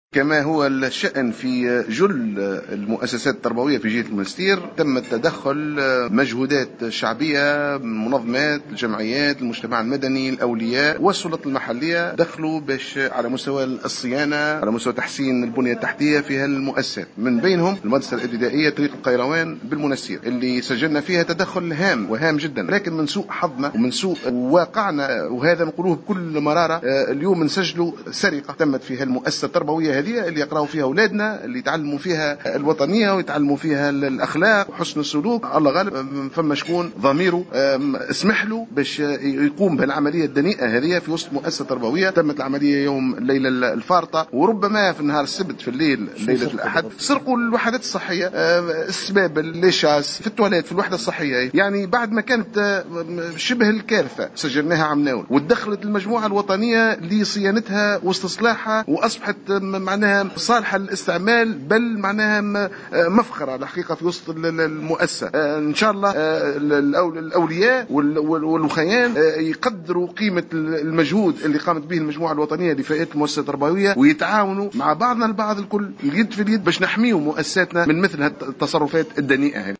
تم فجر اليوم الإثنين 21 سبتمبر 2015 سرقة تجهيزات المدرسة الابتدائية طريق القيروان بالمنستير من قبل مجهولين و فق ما أكده المندوب الجهوي للتربية بالمنستير رضا هنيّة في تصريح للجوهرة اف ام .